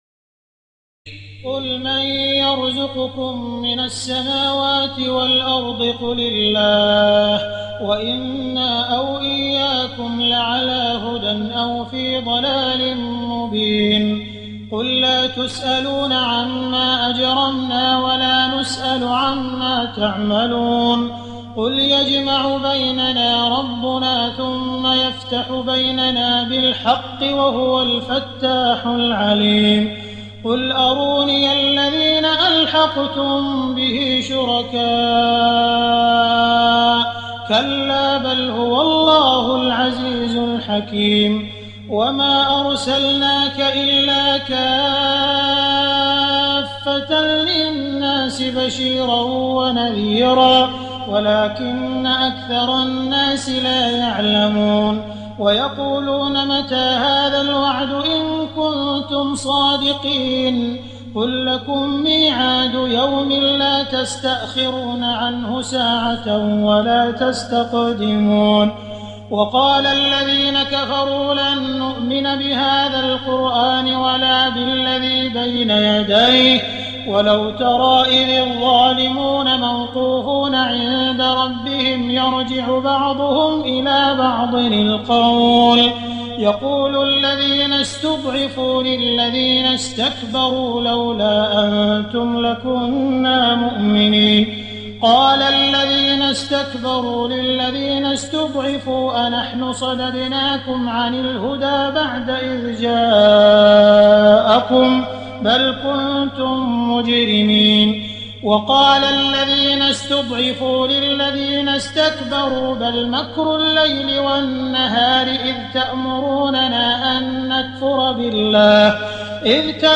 تراويح ليلة 21 رمضان 1419هـ من سور سبأ (24-54) وفاطر و يس(1-32) Taraweeh 21 st night Ramadan 1419H from Surah Saba and Faatir and Yaseen > تراويح الحرم المكي عام 1419 🕋 > التراويح - تلاوات الحرمين